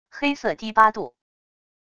黑色低八度wav音频